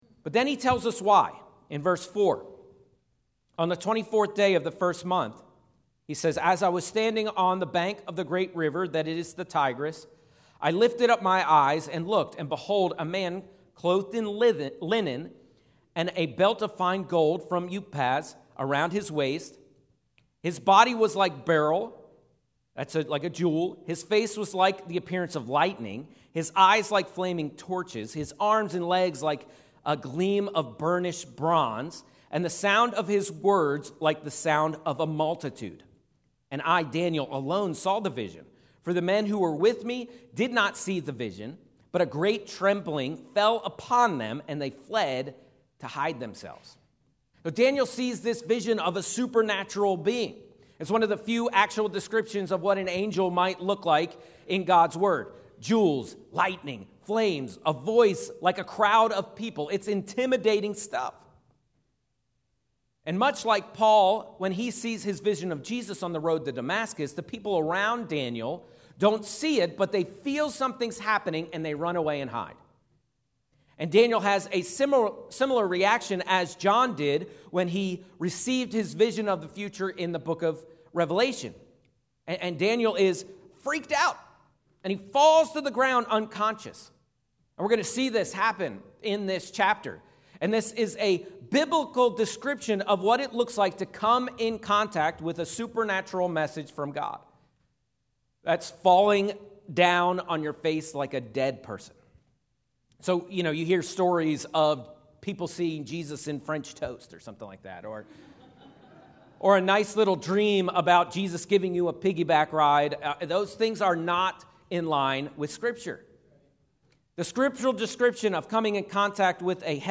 A message from the series "Don't Miss Your Moment."